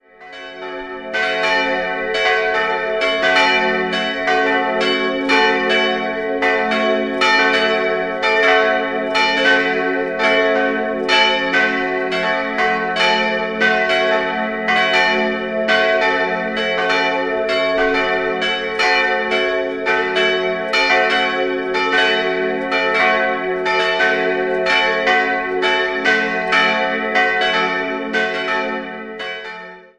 Sehenswert ist der schöne barocke Hochaltar. 3-stimmiges Fis-Moll-Geläut: fis'-a'-cis'' Die beiden größeren Glocken wurden im Jahr 1950 von Karl Czudnochowsky in Erding gegossen, die kleine 1936 von Johann Hahn in Landshut.